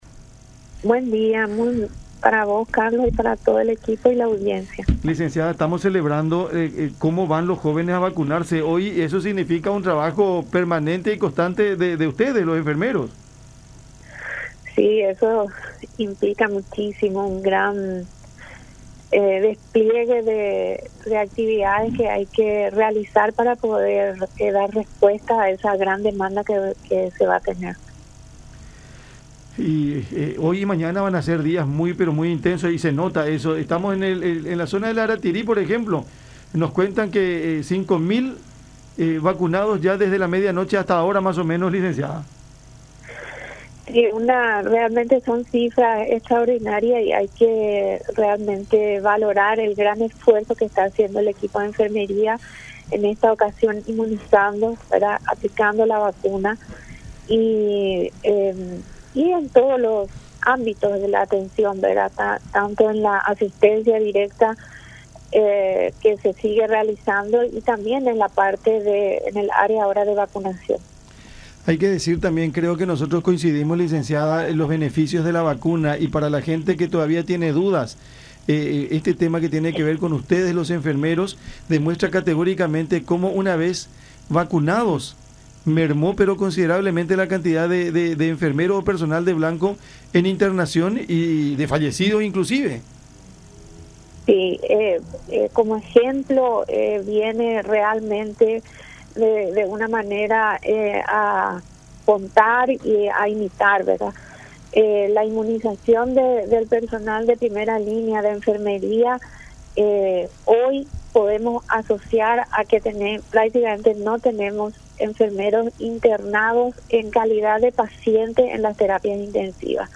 en contacto con La Unión R800 AM